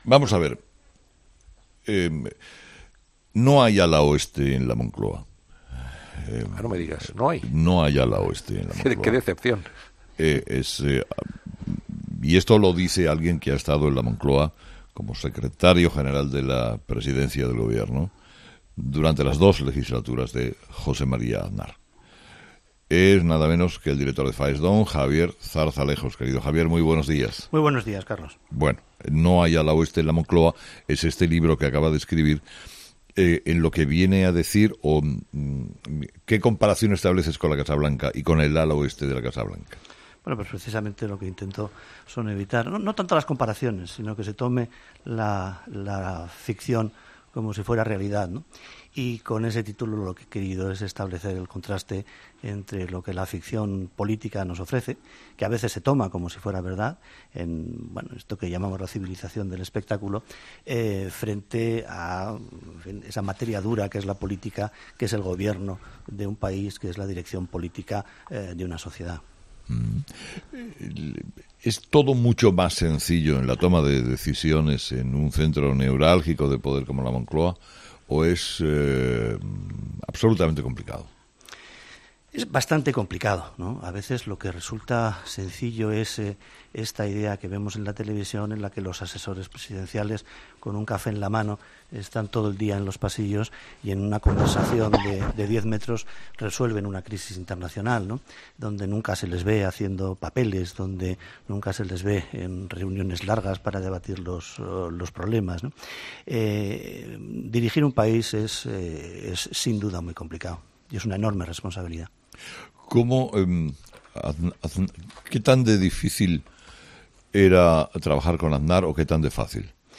Javier Zarzalejos, secretario general de Faes y secretario general de Presidencia durante los gobiernos de José María Aznar, ha sido entrevistado...